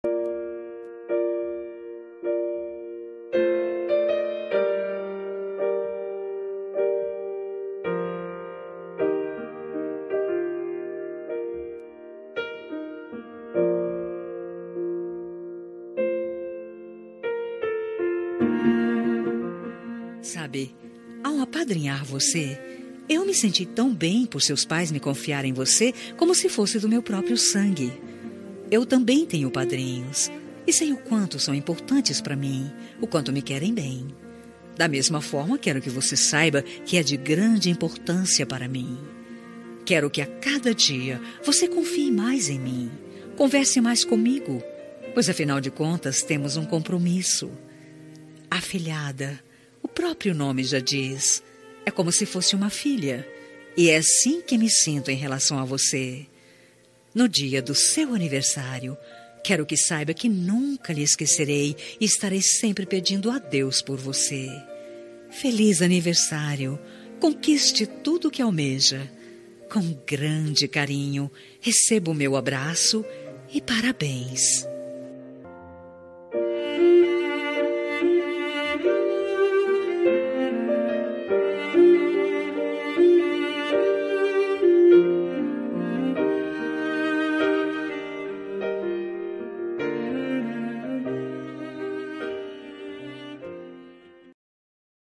Aniversário de Afilhada – Voz Feminina – Cód: 421212